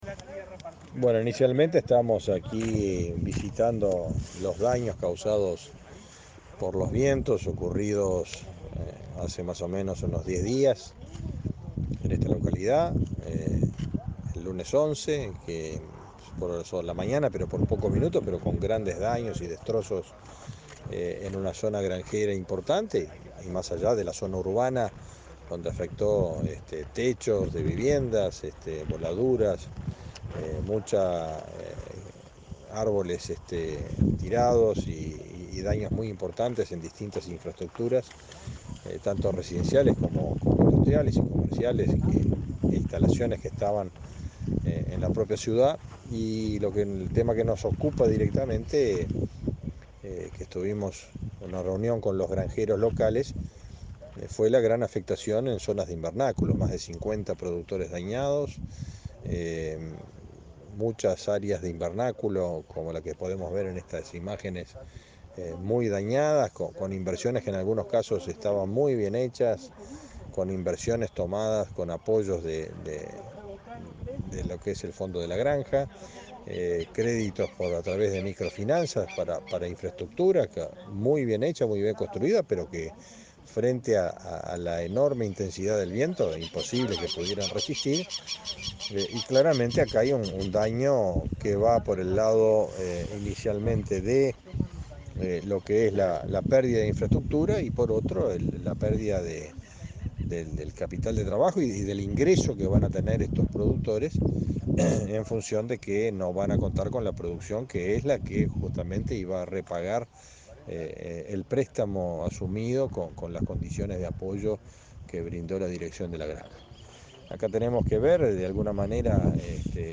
Entrevista al ministro de Ganadería, Fernando Mattos
Este viernes 22, el ministro de Ganadería, Fernando Mattos, dialogó con Comunicación Presidencial durante su recorrida por los departamentos de